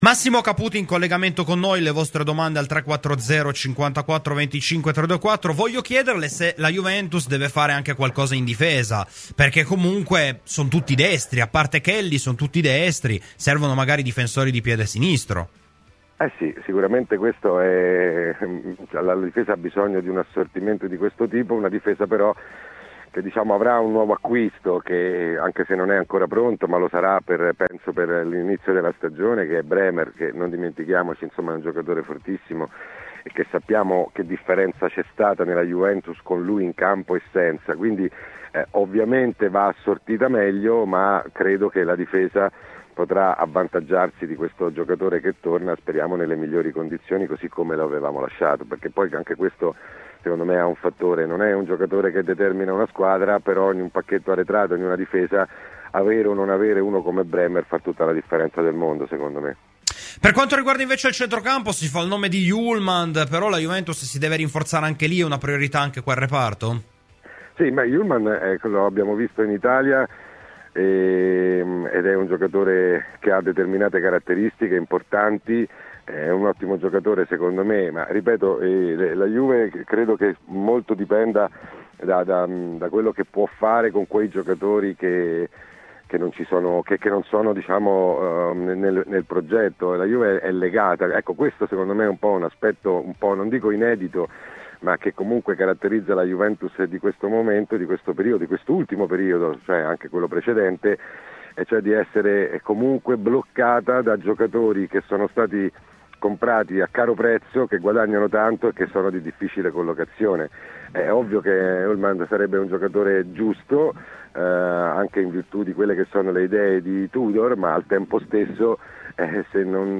Nel corso della "Rassegna Stramba" su Radio Bianconera, Massimo Caputi ha parlato dell'eventualità di un rinforzo in difesa per la Juventus: "In difesa servirebbe effettivamente il giocatore di piede sinistro che manca, ma Tudor nel reparto avrà un nuovo acquisto fondamentale con il recupero di Bremer, avere o non avere uno come lui fa tutta la differenza del mondo.